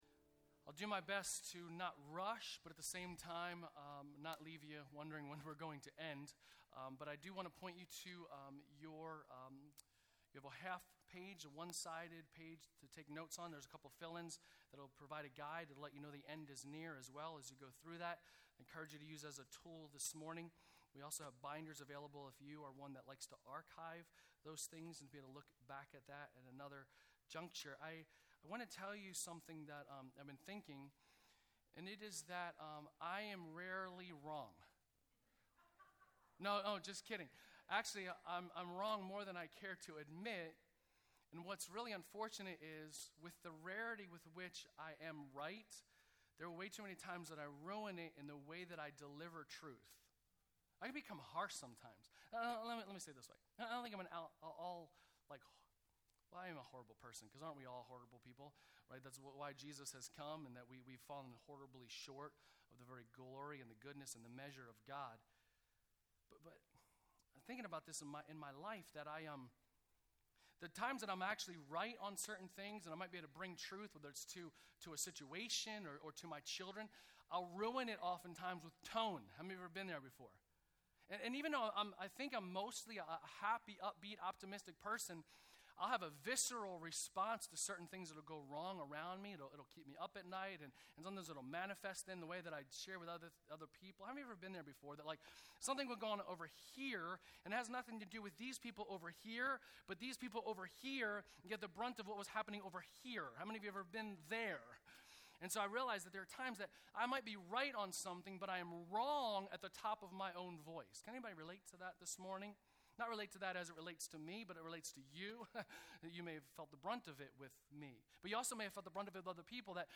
Sermons | Bethany Church